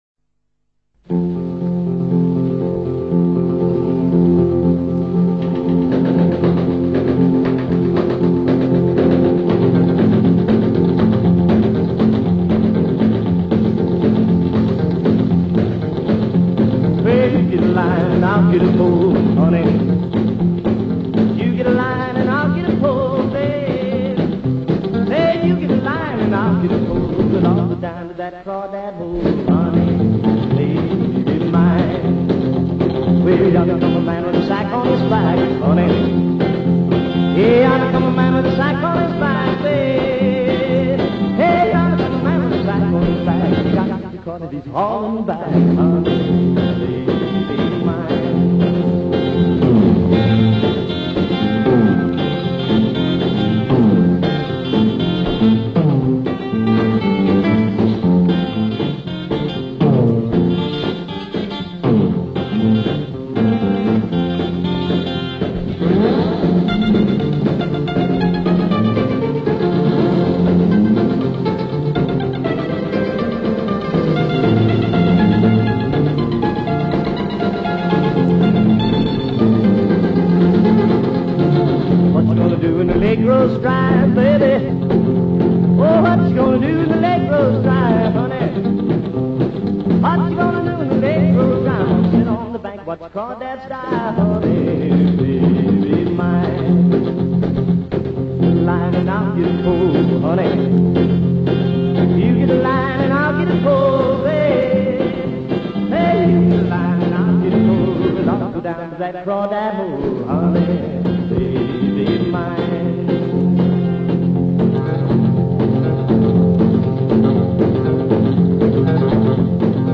restored and remastered from original acetates